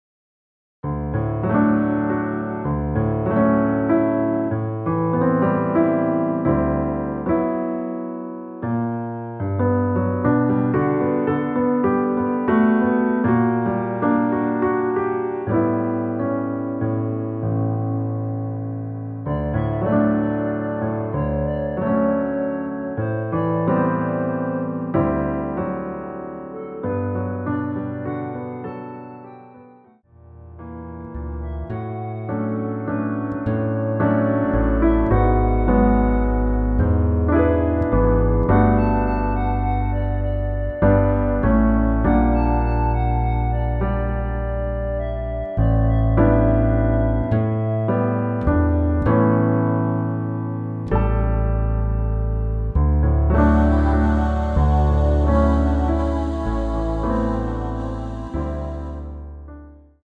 원키 멜로디 포함된 MR입니다.(미리듣기 확인)
D
앞부분30초, 뒷부분30초씩 편집해서 올려 드리고 있습니다.
중간에 음이 끈어지고 다시 나오는 이유는